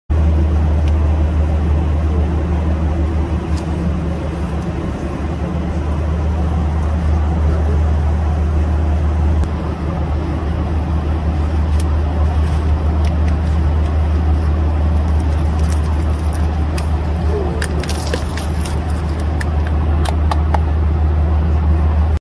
PRINGLES Got Air Pressure At Sound Effects Free Download